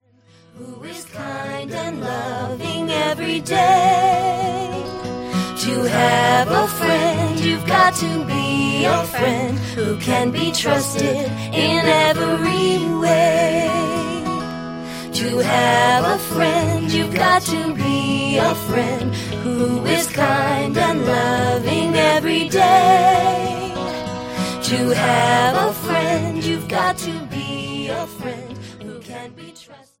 • Good partner song
• A-B form